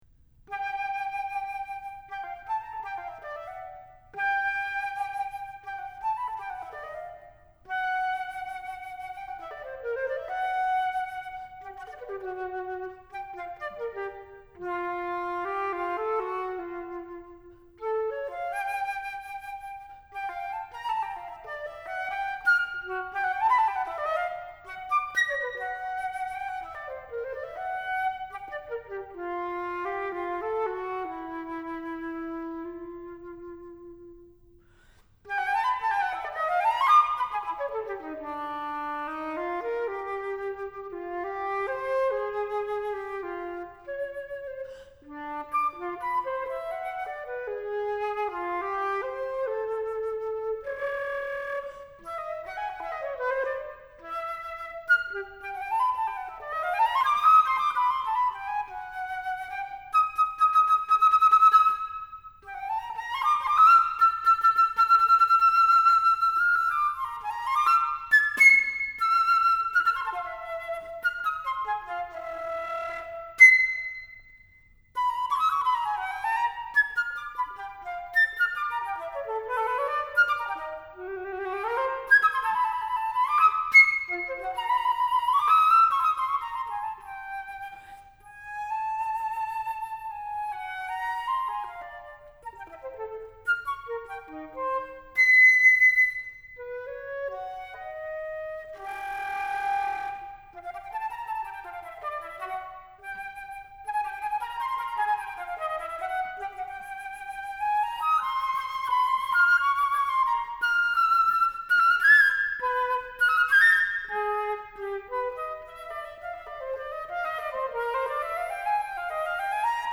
Solo – Flute
A virtuosic piece